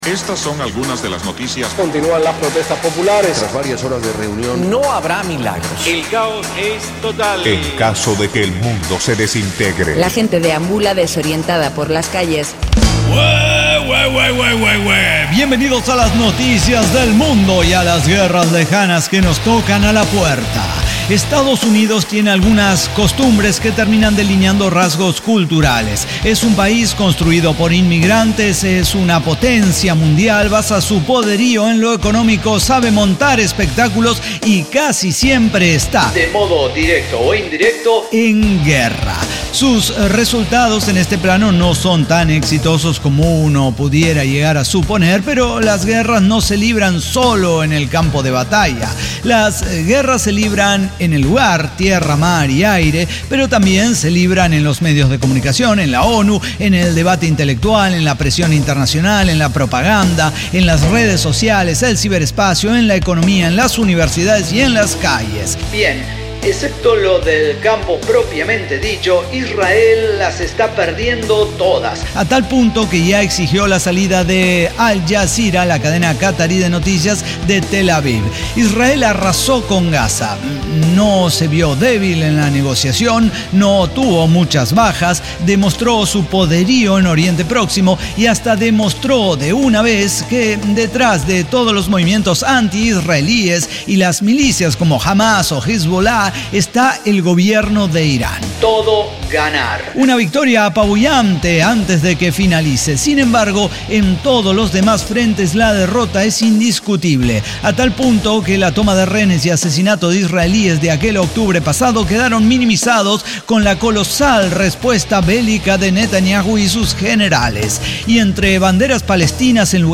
ECDQEMSD podcast El Cyber Talk Show – episodio 5776 Gaza En Casa